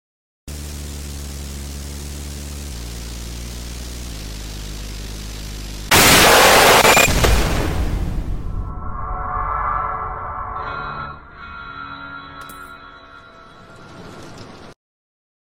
Rush jumpscare in roblox doors sound effects free download